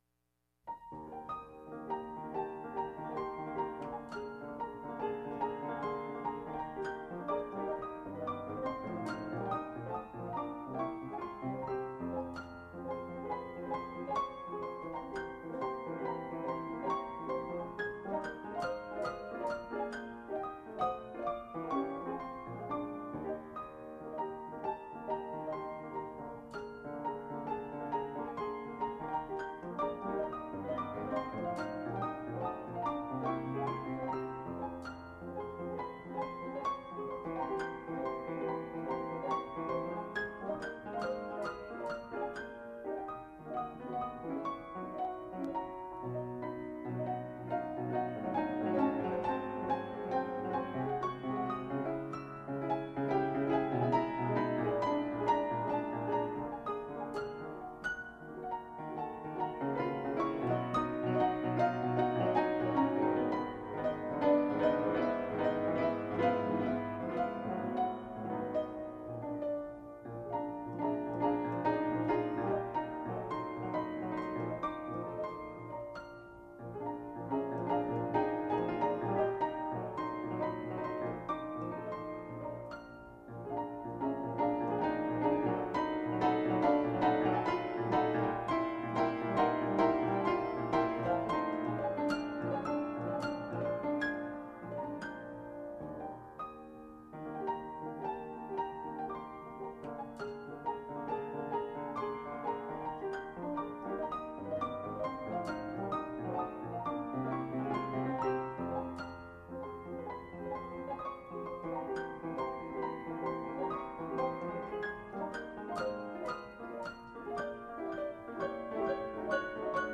Piano  (View more Advanced Piano Music)
Classical (View more Classical Piano Music)